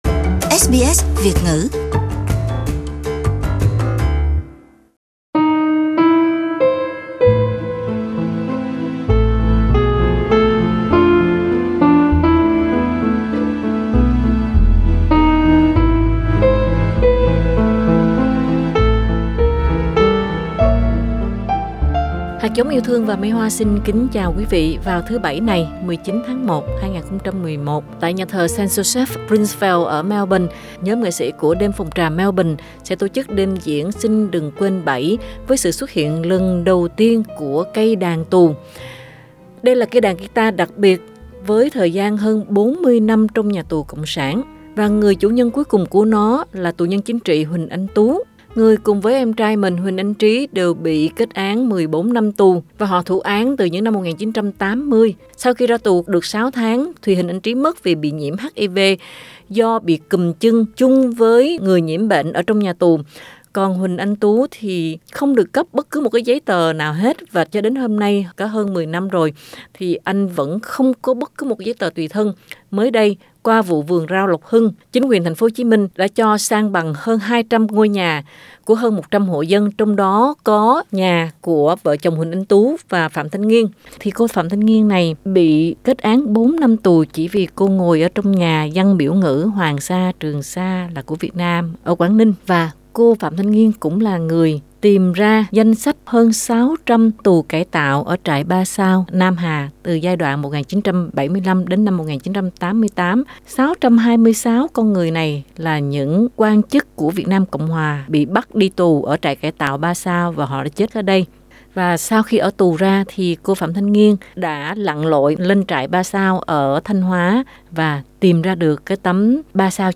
Các nghệ sĩ của Đêm Phòng Trà Melbourne gởi chút tình đồng bào đến các TNLT và nạn nhân mất nhà ở Vườn Rau Lộc Hưng trong những ngày giáp tết qua chương trình văn nghệ đặc sắc Xin Đừng Quên với sự xuất hiện lần đầu tiên của Cây Đàn Tù.